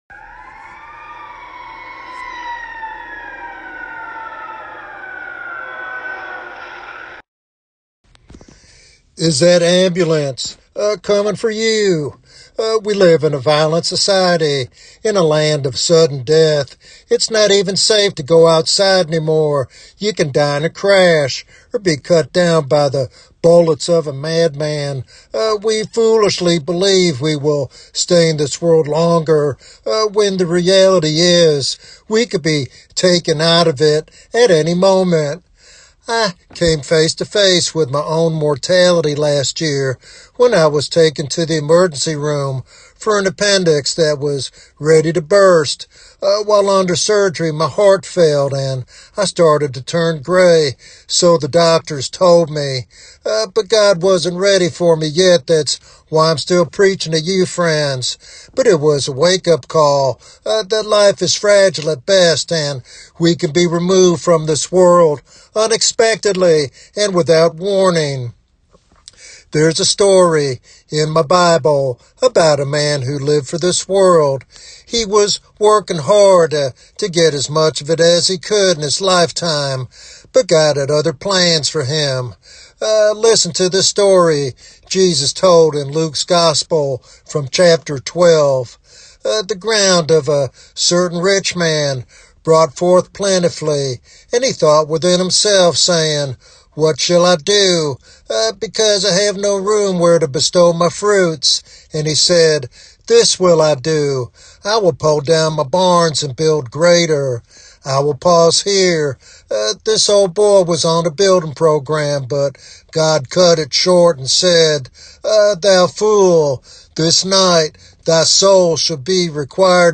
Sermon Outline